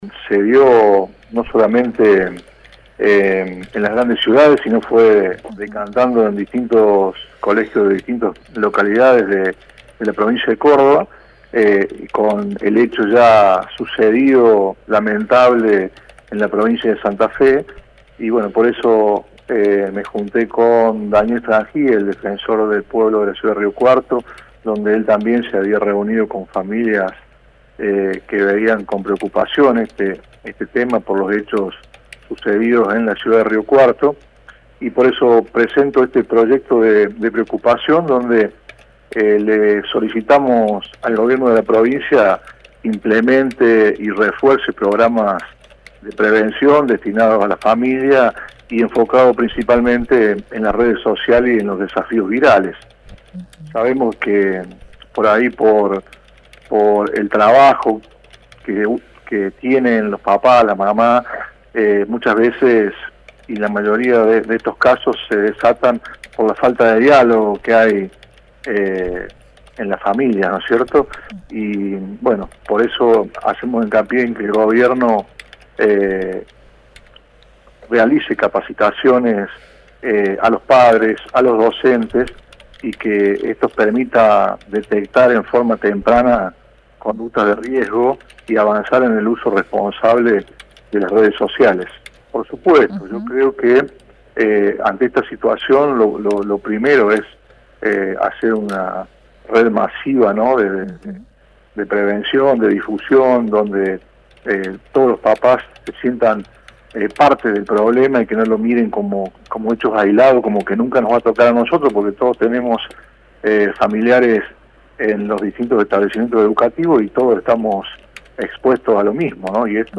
En diálogo con Universidad, el legislador Grich le solicita al gobierno de la provincia que refuerce programas de prevención en las familias.